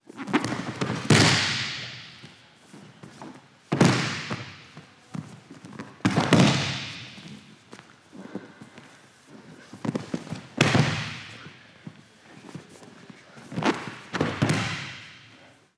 Varias caídas por golpes de judo 2